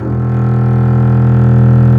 Index of /90_sSampleCDs/Roland - String Master Series/STR_Cb Bowed/STR_Cb2 f vb